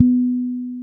-MM DUB  B 4.wav